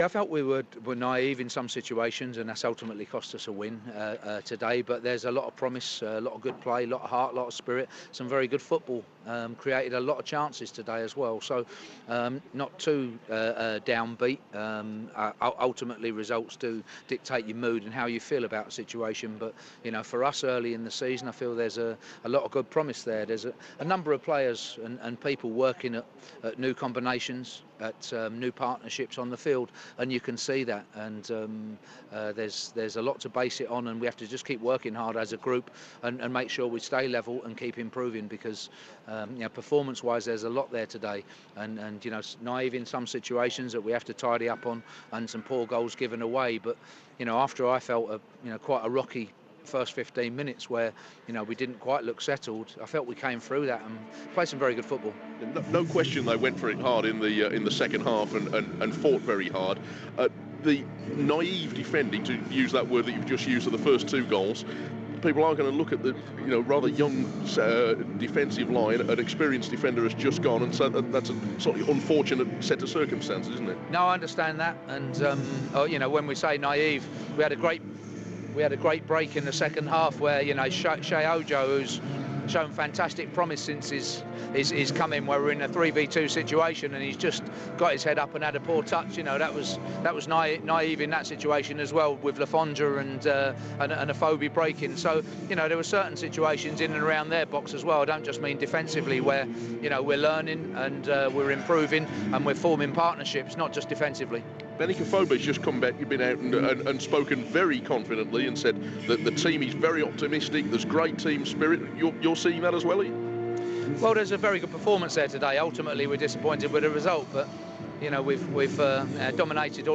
post-match at the Macron Stadium.